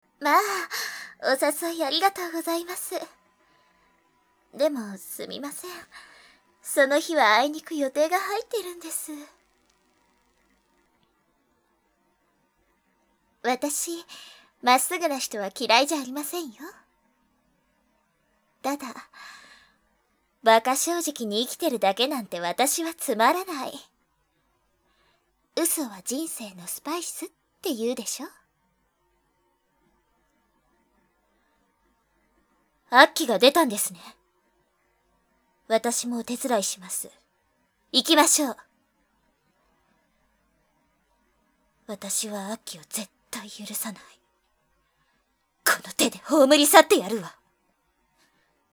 演じていただきました！
年上年下関係なく敬語で話し、距離を置いている節がある。